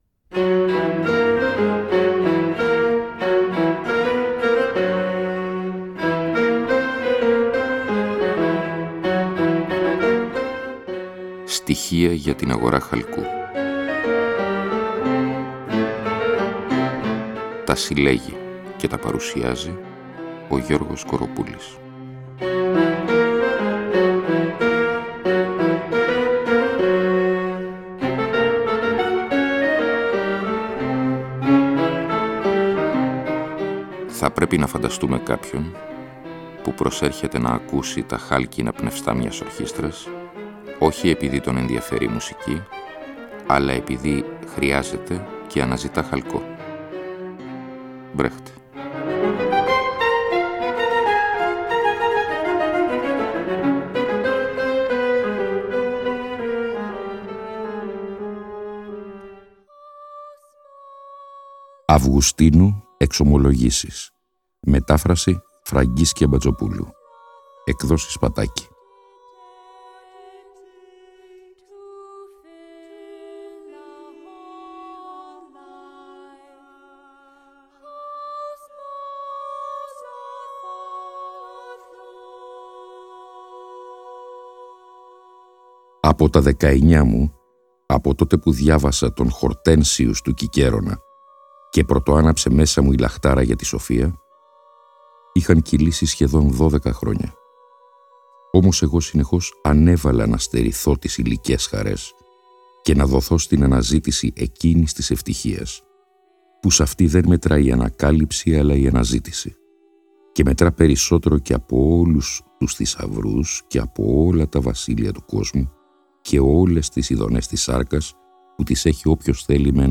Εκπομπή λόγου. Ακούγονται, ερμηνεύονται και συγκρίνονται με απροσδόκητους τρόπους κείμενα λογοτεχνίας, φιλοσοφίας, δοκίμια κ.λπ. Η διαπλοκή του λόγου και της μουσικής αποτελεί καθ εαυτήν σχόλιο, είναι συνεπώς ουσιώδης.